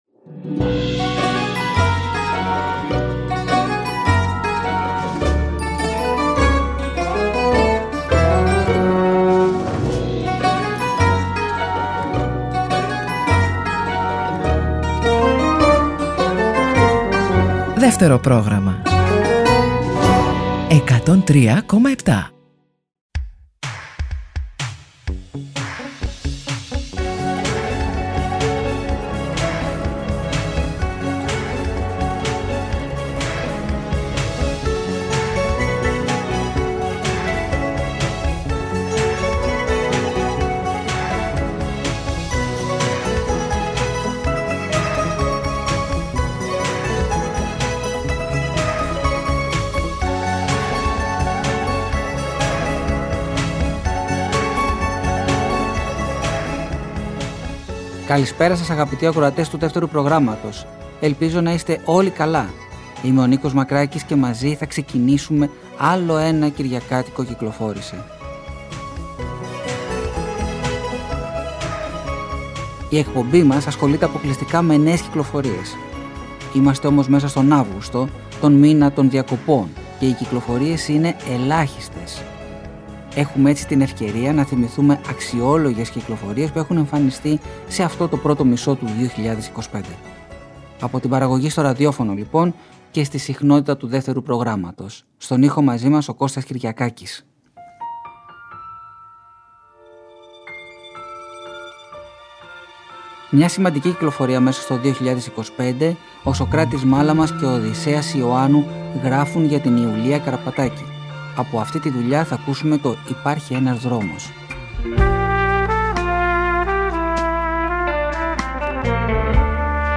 Μία ακόμα εκπομπή με θέμα τις νέες κυκλοφορίες προστίθεται στο Δεύτερο Πρόγραμμα 103,7.